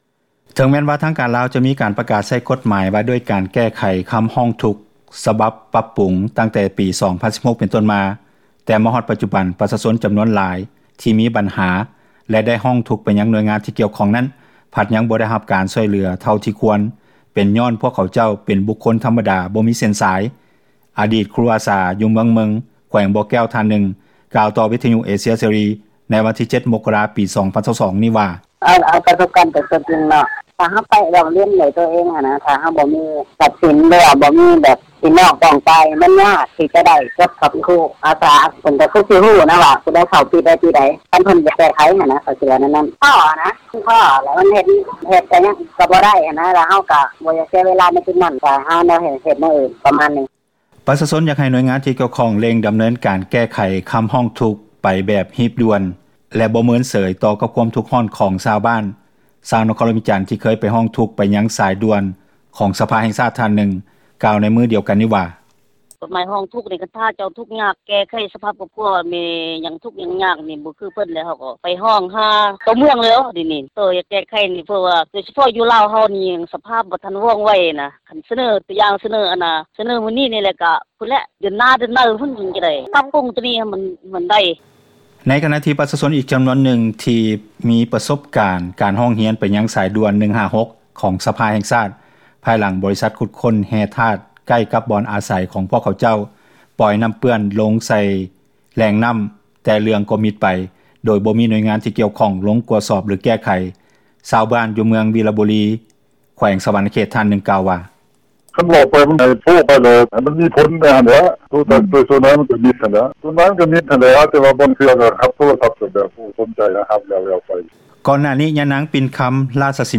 ເຈົ້າໜ້າທີ່ທີ່ກ່ຽວຂ້ອງຍານາງນຶ່ງກ່າວວ່າ:
ເຈົ້າໜ້າທີ່ທີ່ກ່ຽວຂ້ອງອີກຍານາງນຶ່ງກ່າວວ່າ: